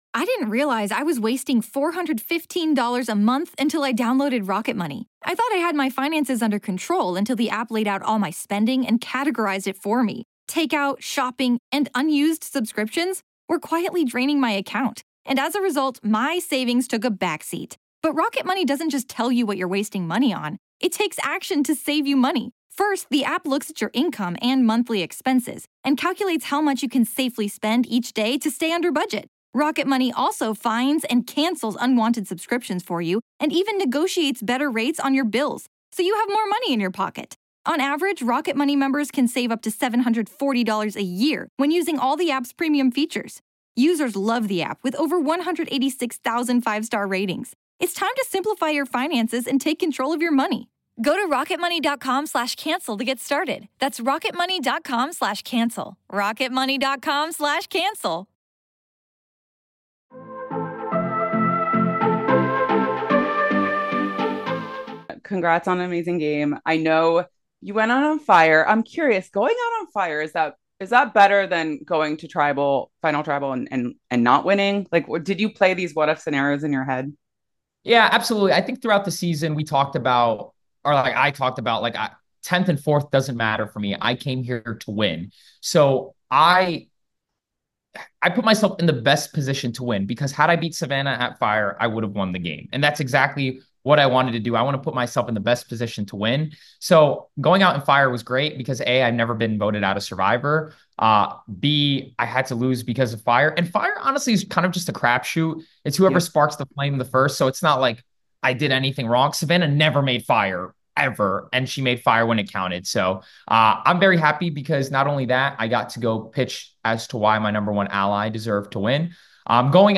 Survivor 49 Exit Interview